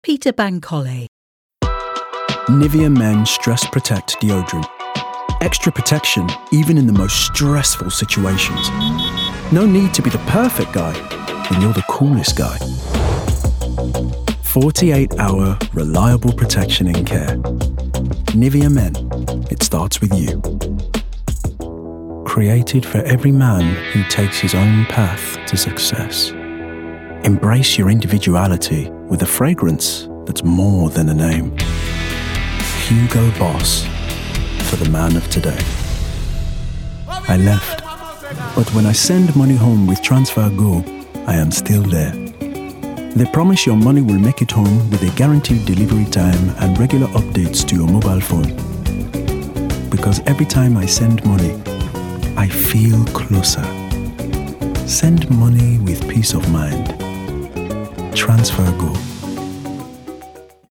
Description: Neutral: relatable, assured, mellow
Commercial 0:00 / 0:00
Midlands*, Neutral*, RP